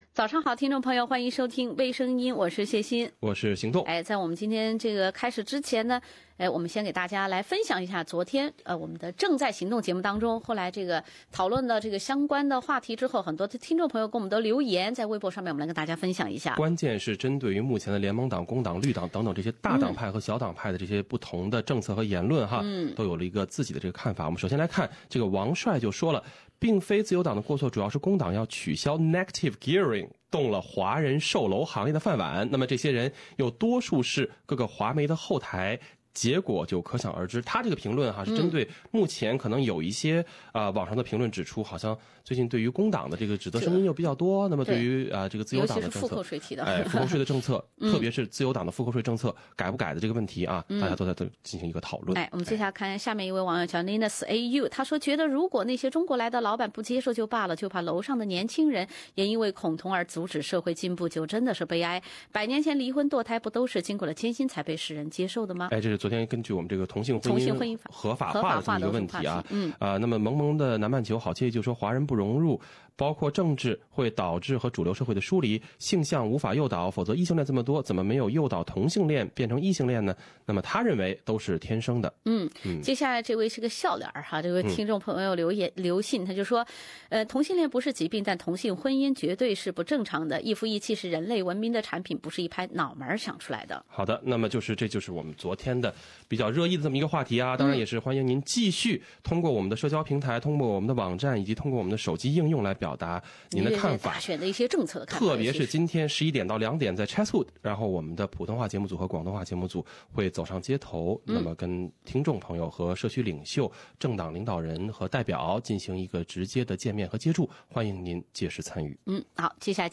另類輕松的播報方式，深入淺出的辛辣點評；包羅萬象的最新資訊；傾聽全球微聲音。